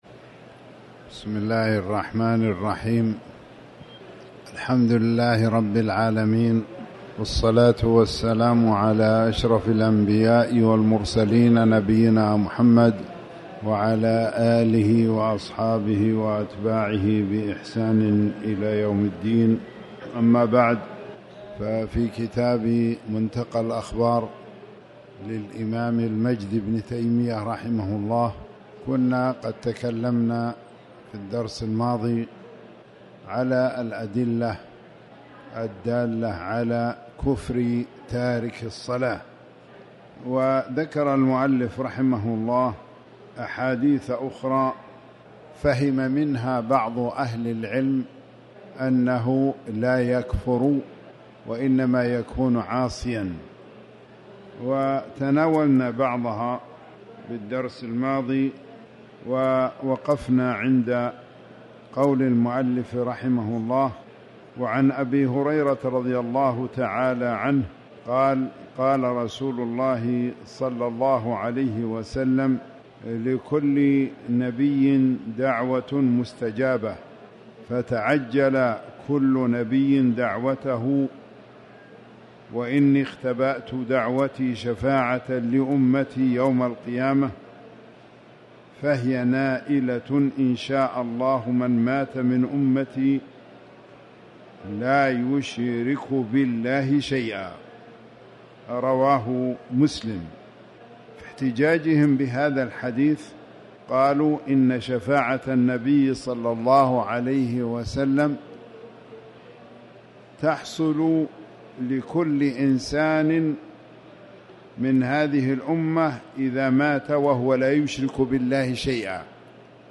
تاريخ النشر ٢٩ شعبان ١٤٣٩ هـ المكان: المسجد الحرام الشيخ